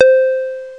CountDown.wav